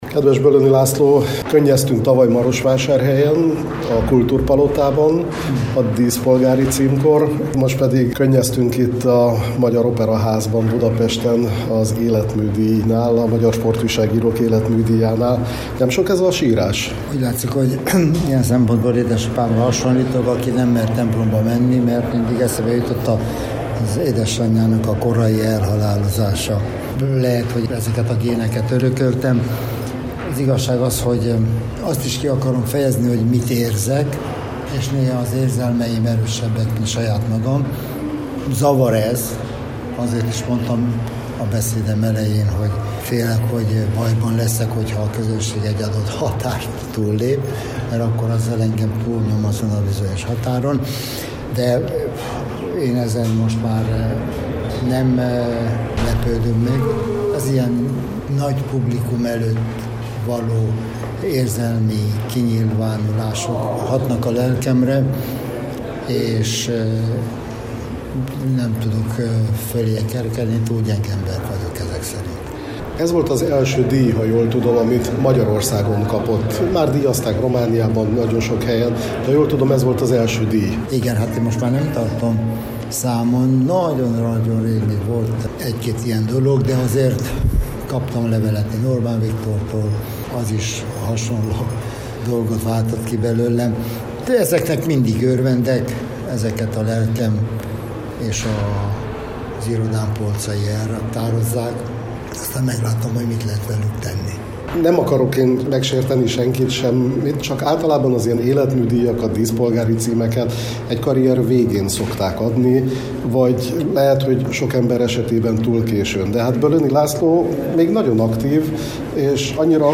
Az eseményen a romániai médiából egyedüliként jelen volt a Marosvásárhelyi Rádió is.